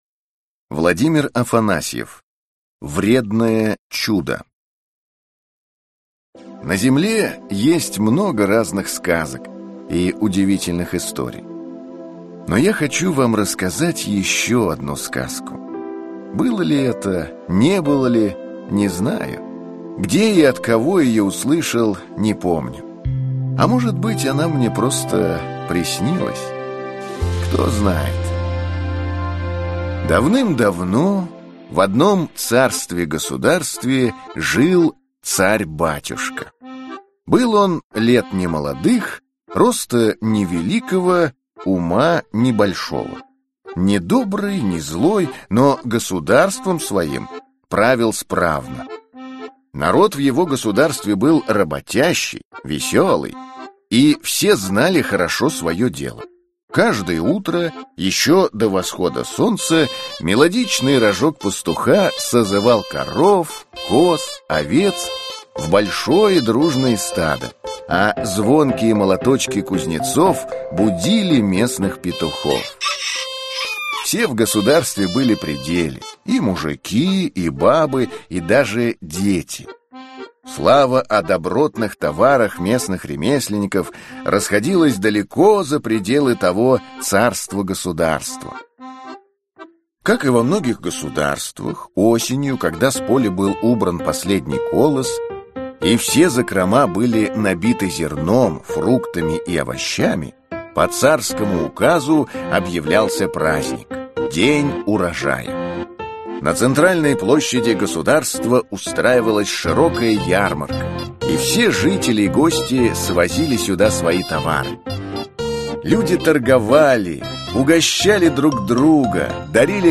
Аудиокнига Вредное чудо | Библиотека аудиокниг